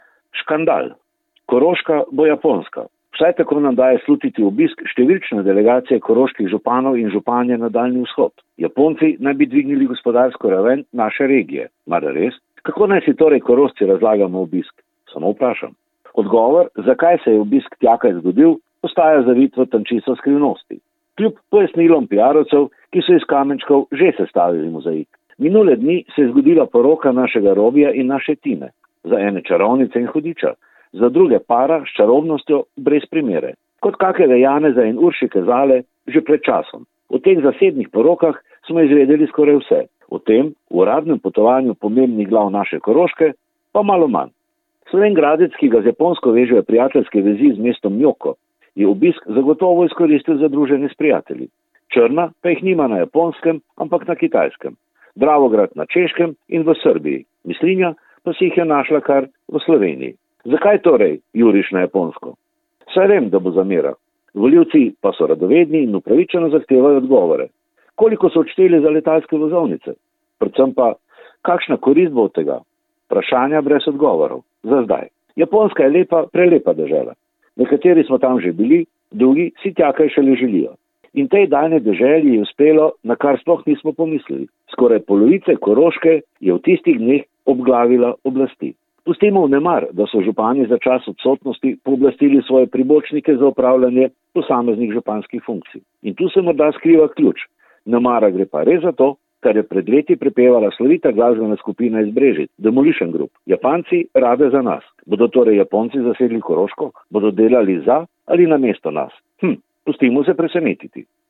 komentar.mp3